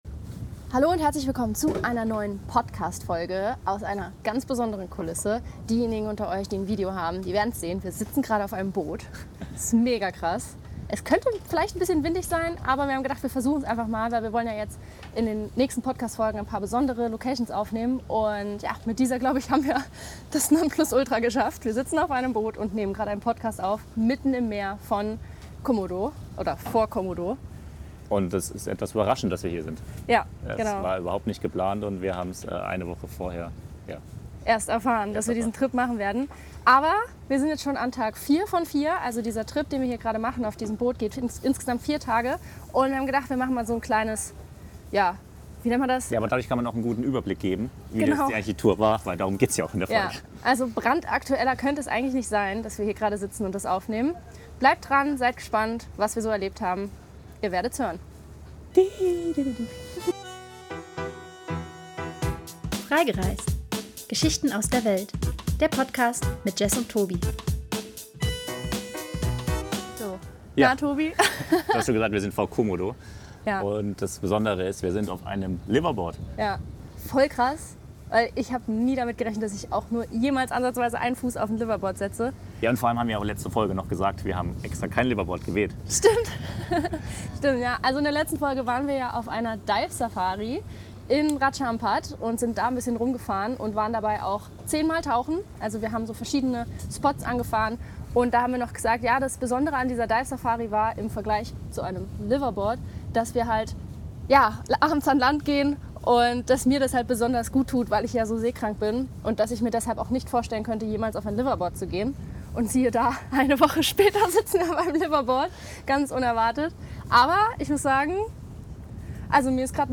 erzählen in dieser Podcastfolge live vom Boot aus